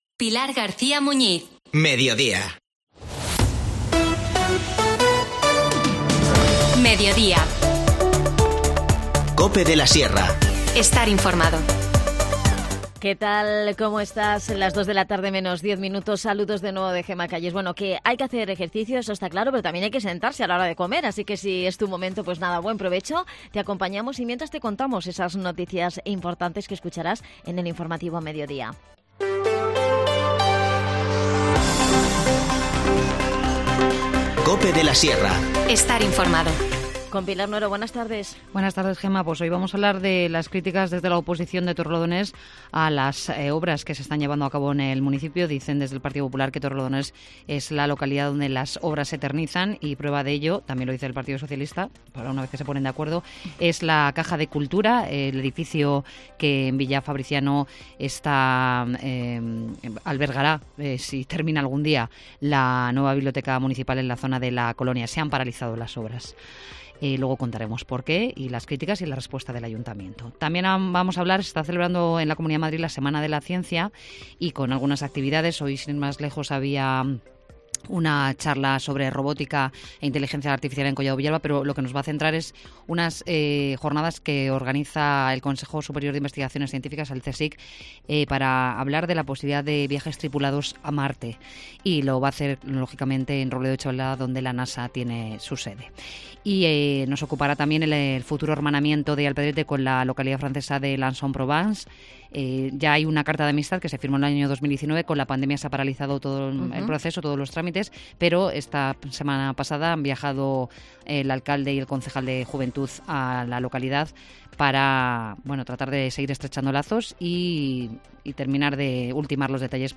Hablamos con Jose Antonio Herrera, concejal de Desarrollo Local, Turismo y Comercio.